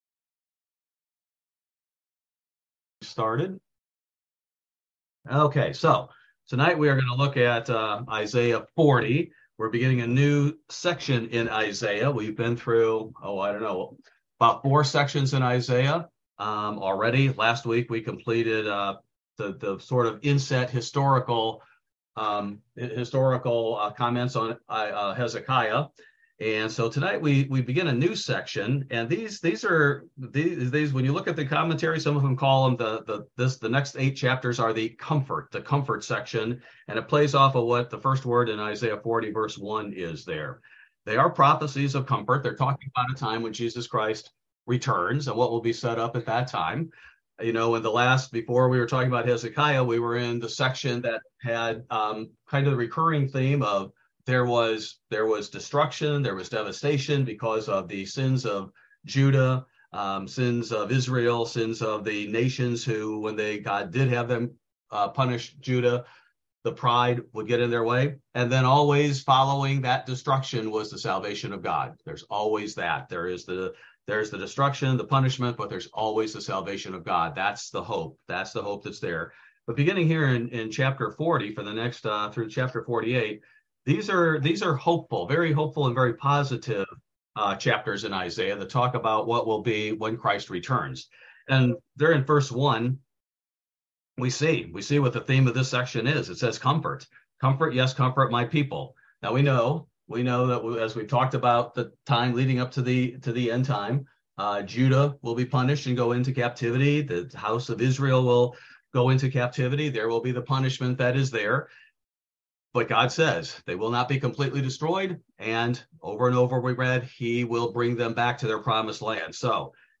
This verse by verse Bible study focuses primarily on Isaiah 40: Prophecy of Comfort